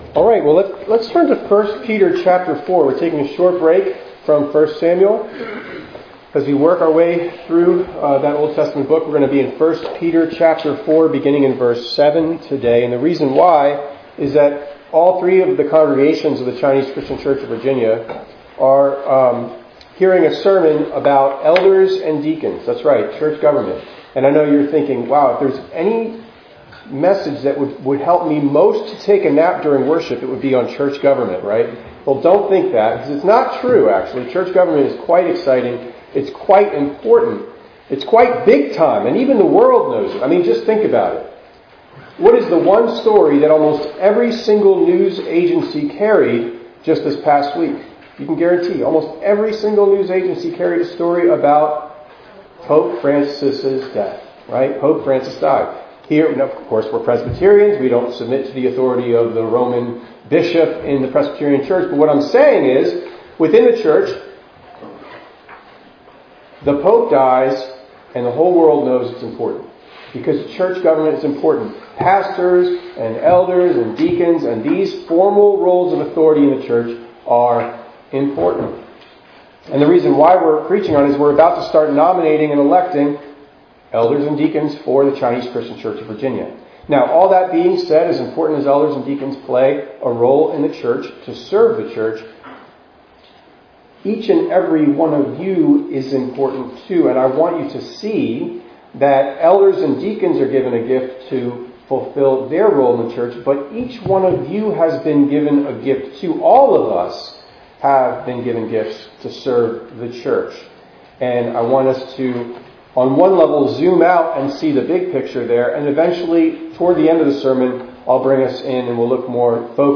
4_27_25_ENG_Sermon.mp3